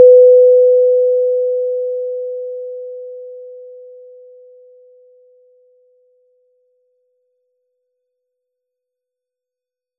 Aquí se presentan los siguientes sonidos generados mediante goldwave que ilustran los fenómenos del umbral no lineal de audición, los efectos de enmascaramiento de una señal o la percepción logarítmica de la potencia de los sonidos.
Percepción logarítmica: Generamos la siguiente señal mediante el goldwave: sin(2*pi*f*t)*exp(-t);si la escuchamos vemos como aunque el decaimiento de la potencia es exponencial a nosotros nos parece que es lineal.
expon-simple.wav